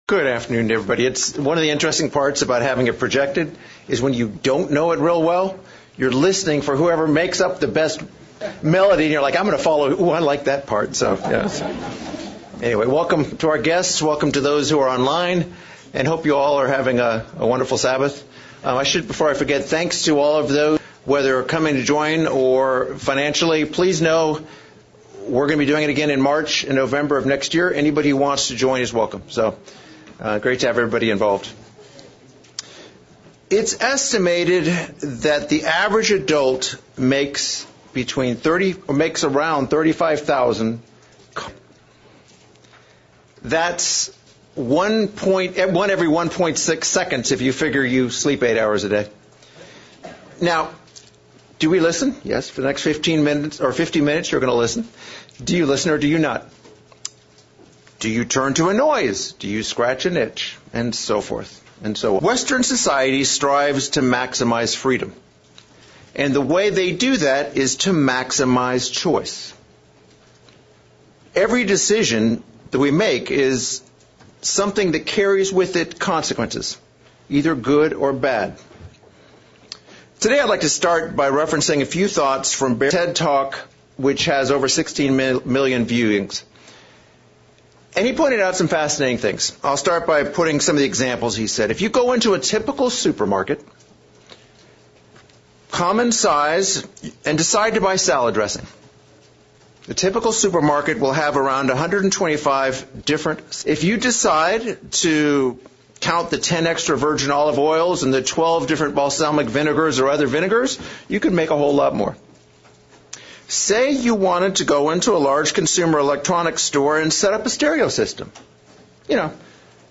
Sermon looking at the subject of the choices we make?